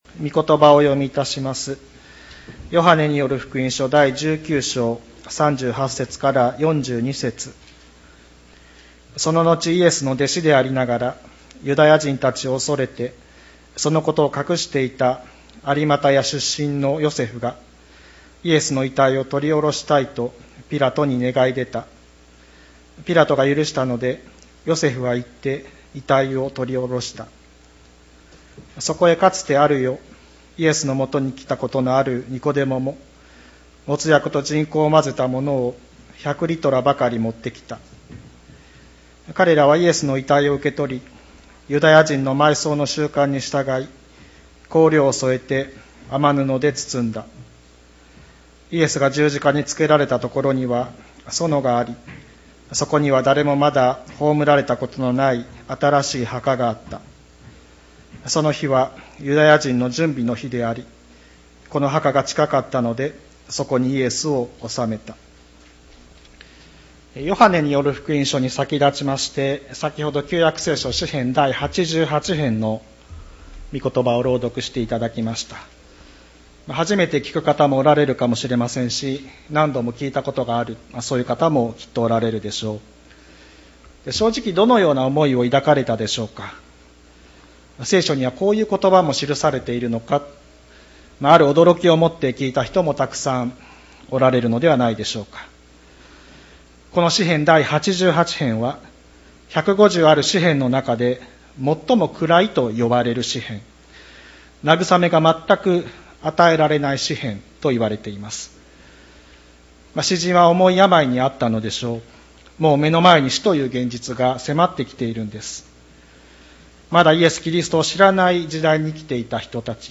千里山教会 2020年10月18日の礼拝メッセージ。